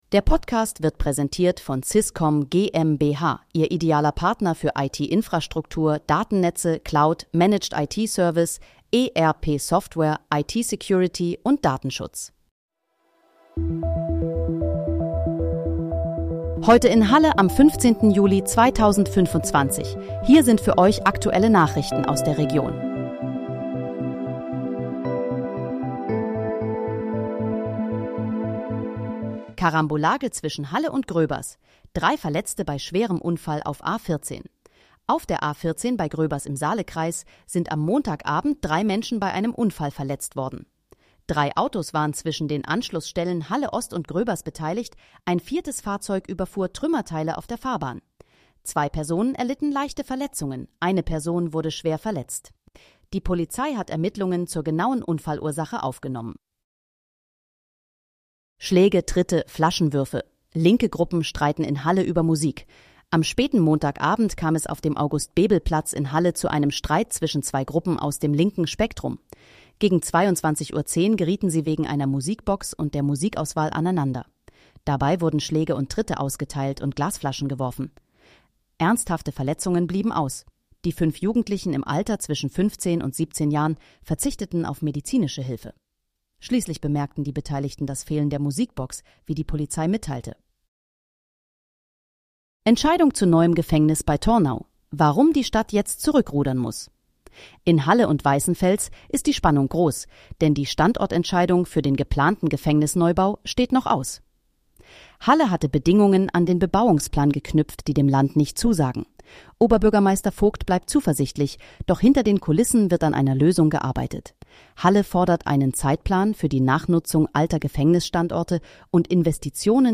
Heute in, Halle: Aktuelle Nachrichten vom 15.07.2025, erstellt mit KI-Unterstützung
Nachrichten